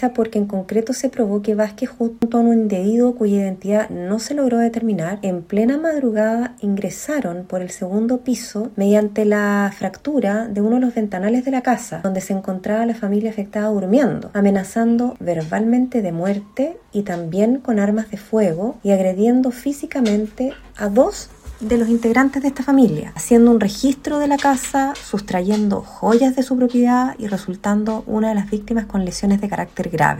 Según lo expuesto por la jueza Ana María Sauterel, durante el juicio se logró acreditar el uso de violencia extrema por parte del condenado, además de su reincidencia, considerando un hecho previo en el que causó lesiones graves a las víctimas.
cunas-ana-maria-sauterer-jueza.mp3